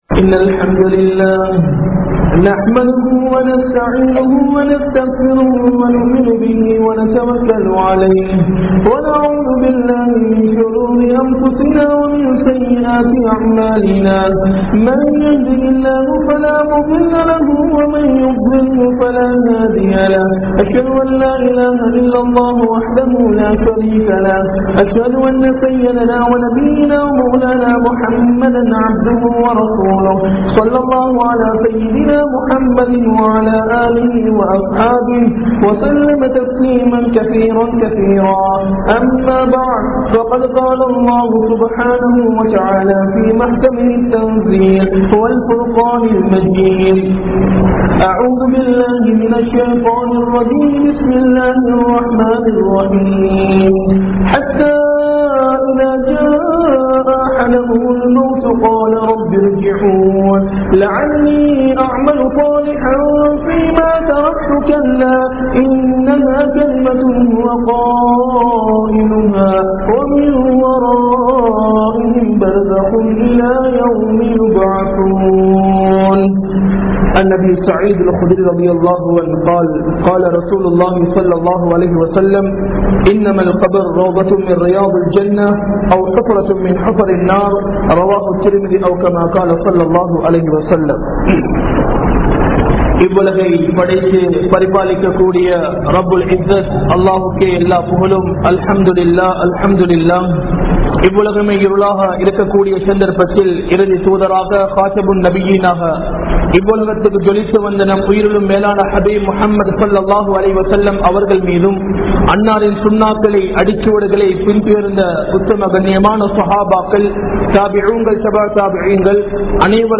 05 Vahaiyana Ulahangal (05 வகையான உலகங்கள்) | Audio Bayans | All Ceylon Muslim Youth Community | Addalaichenai
Colombo 07, Jawatha Jumua Masjith